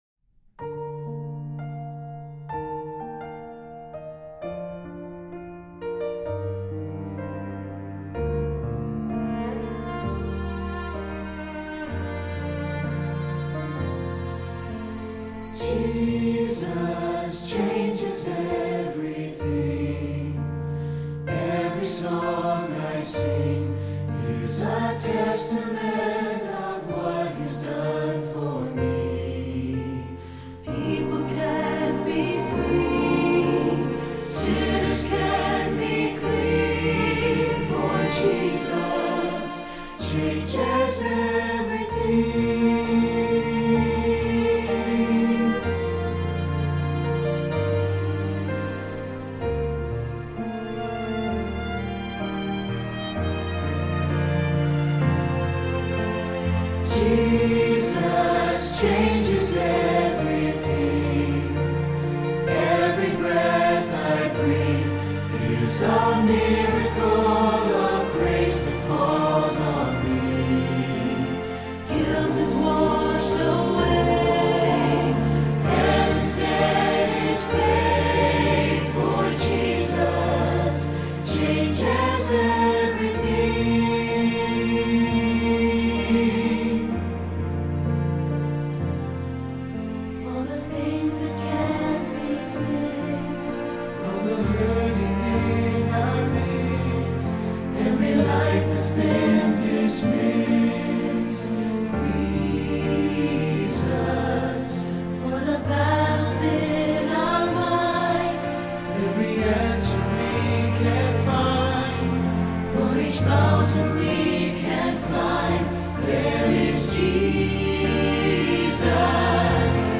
Choral Church
SATB